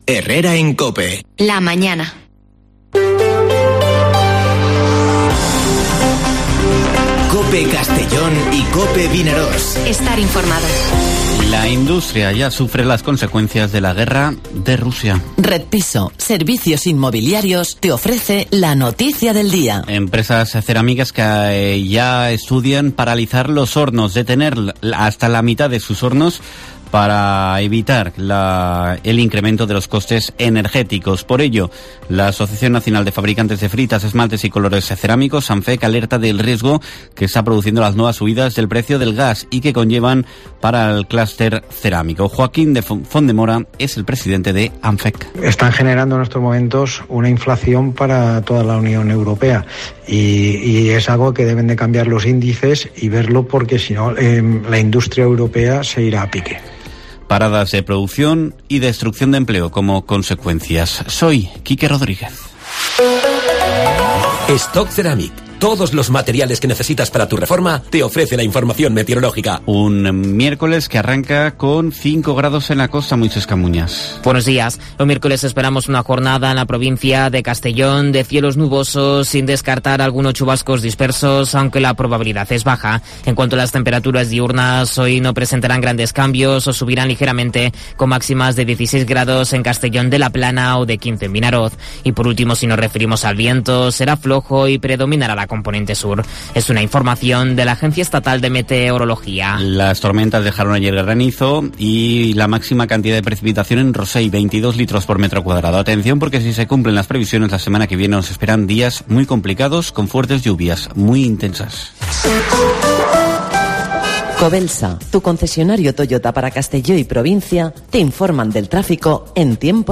Informativo Herrera en COPE en la provincia de Castellón (09/03/2022)